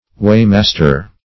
Search Result for " weighmaster" : The Collaborative International Dictionary of English v.0.48: Weighmaster \Weigh"mas`ter\, n. One whose business it is to weigh ore, hay, merchandise, etc.; one licensed as a public weigher.